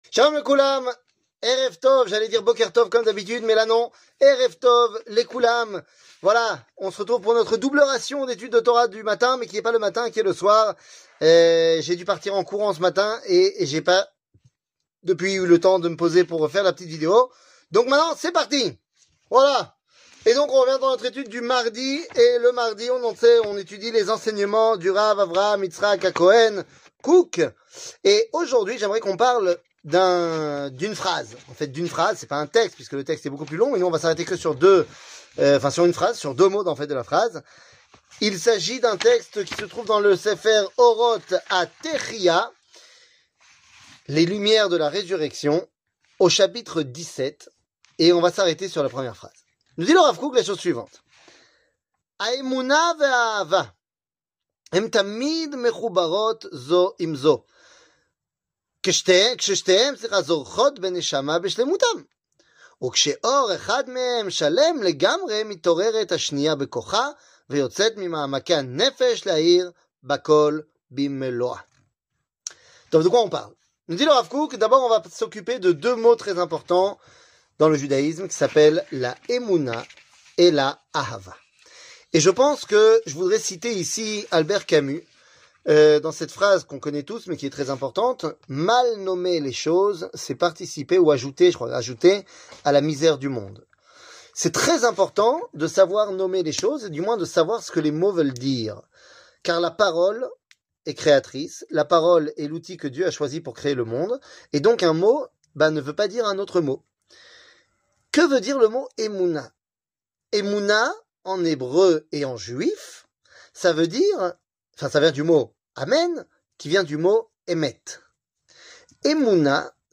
Rav Kook, Orot Atehiya, Emouna et Aava 00:06:13 Rav Kook, Orot Atehiya, Emouna et Aava שיעור מ 28 נובמבר 2023 06MIN הורדה בקובץ אודיו MP3 (5.69 Mo) הורדה בקובץ וידאו MP4 (8.99 Mo) TAGS : שיעורים קצרים